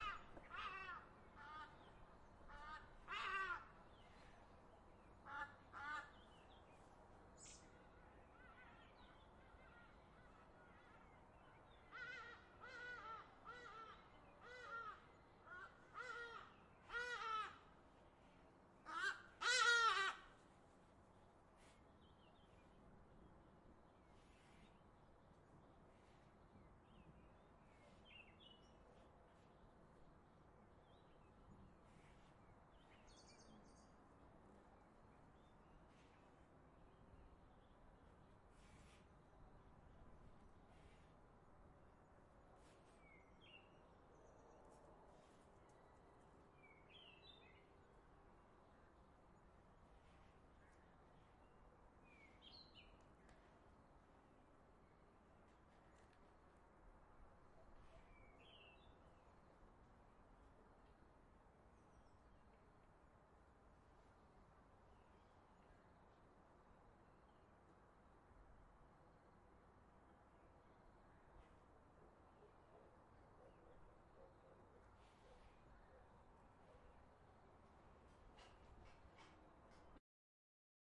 描述：大自然的宁静，听起来好像你在某种鸟类公园内。
Tag: 森林 公园 春天 自然 现场录音 OWI 氛围